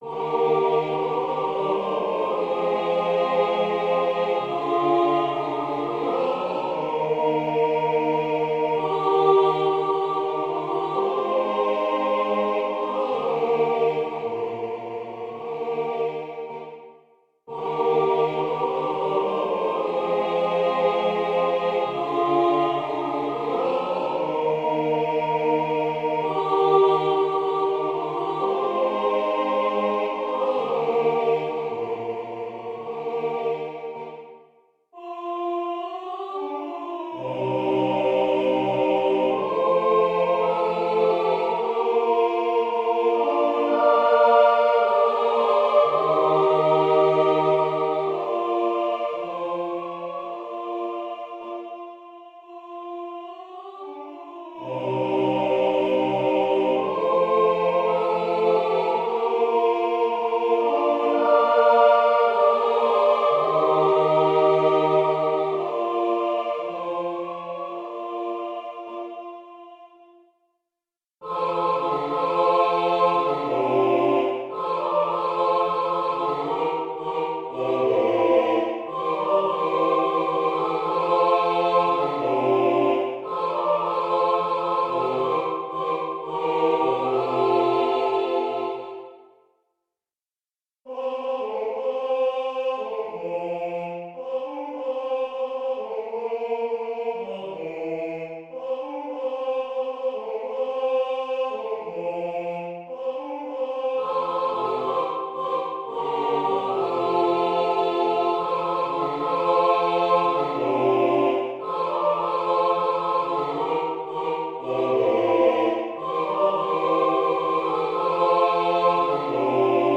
Partitions et enregistrements audio séquenceur du morceau Spiritual Contrasts, de Harold L. Walters, Negro spiritual.